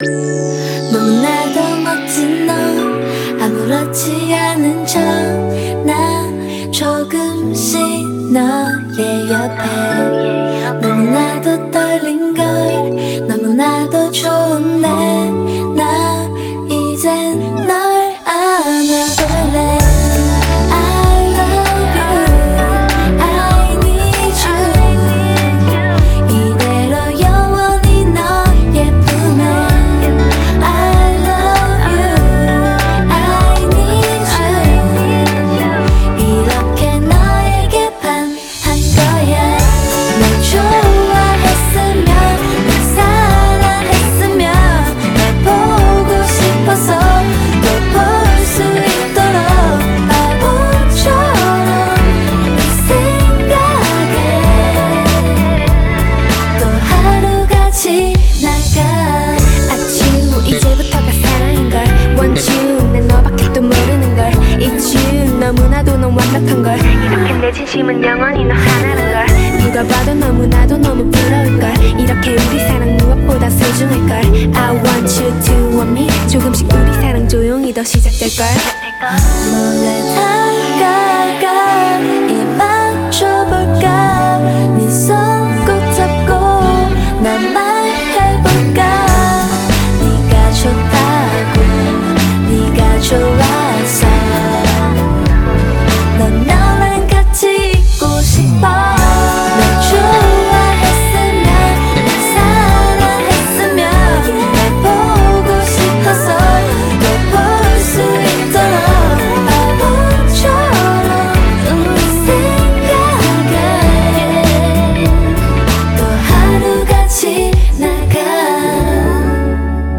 BPM97
Audio QualityMusic Cut
A more mellow tinge to this song here.